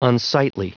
Prononciation du mot unsightly en anglais (fichier audio)
Prononciation du mot : unsightly